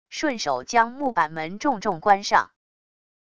顺手将木板门重重关上wav音频